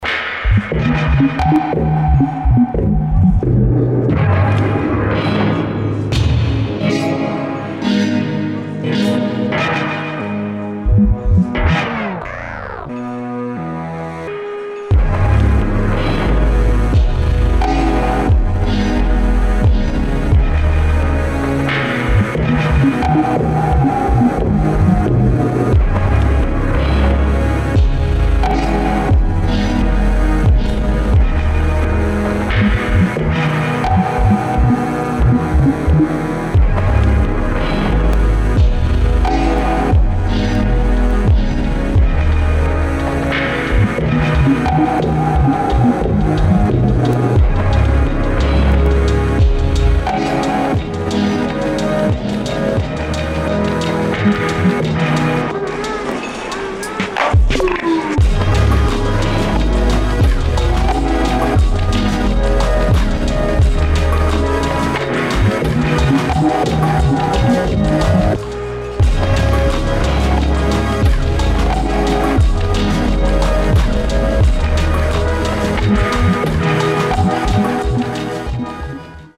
[ DOWNBEAT / ELECTRONIC / DUBSTEP ]